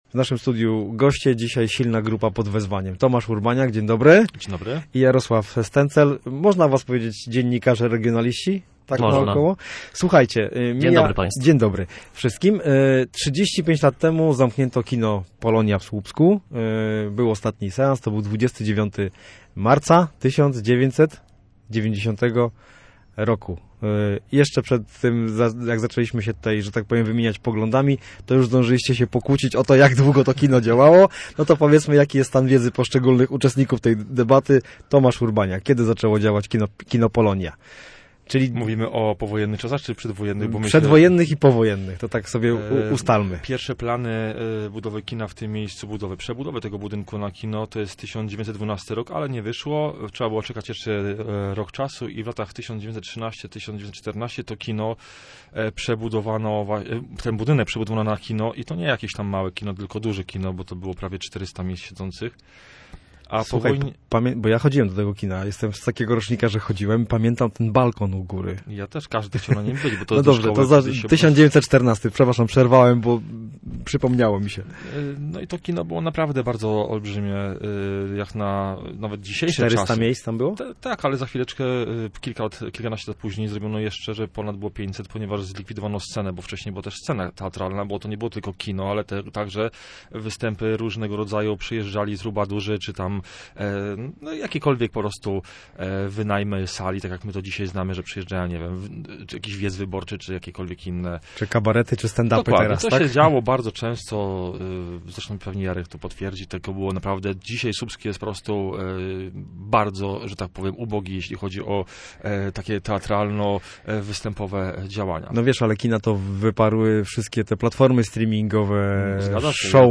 Posłuchaj rozmowy o kinie Polonia w Słupsku: